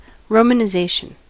Romanization: Wikimedia Commons US English Pronunciations
En-us-Romanization.WAV